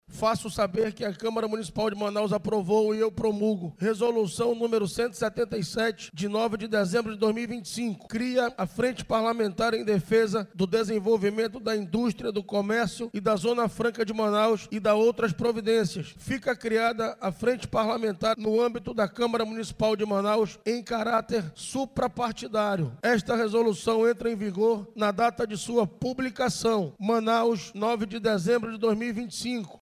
A Frente Parlamentar em Defesa do Desenvolvimento da Indústria, do Comércio e da Zona Franca de Manaus vai funcionar em caráter suprapartidário, ou seja, com a participação de todos os partidos políticos, mas não estando subordina a nenhum deles, destacou o presidente do Parlamento Municipal, o vereador David Reis.